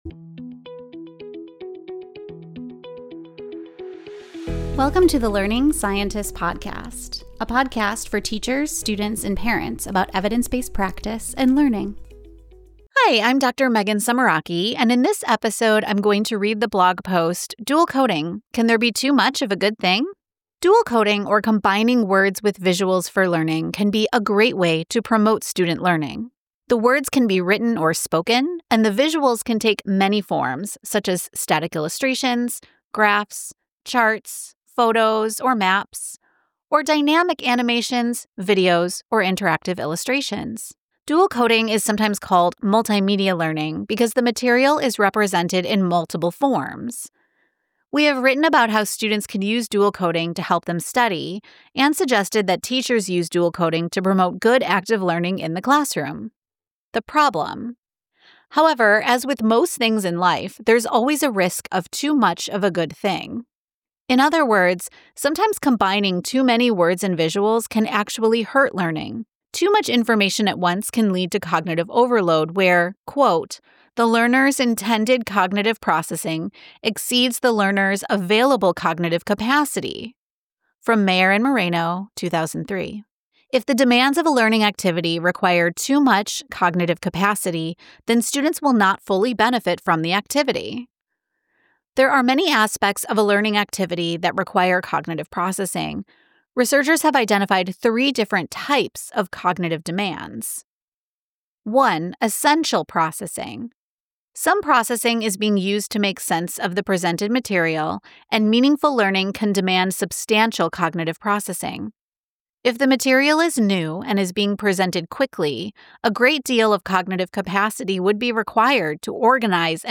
reads her blog post